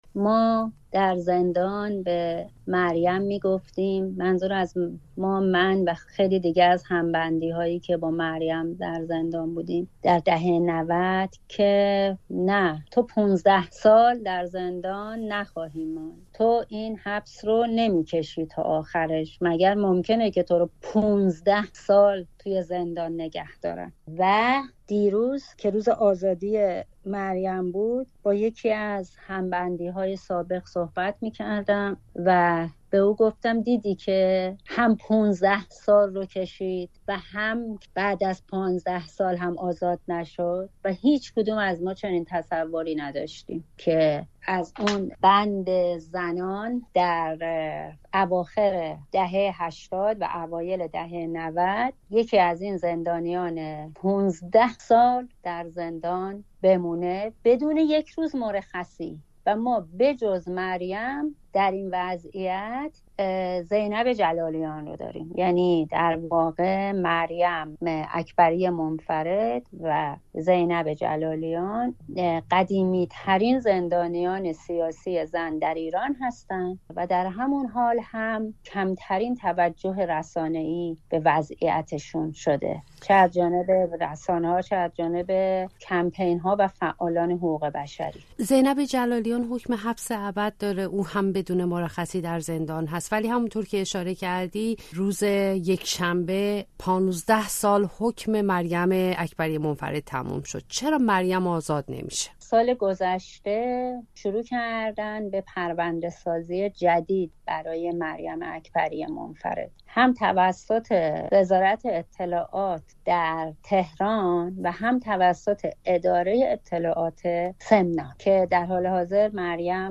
در گفتگو با رادیوفردا می‌گوید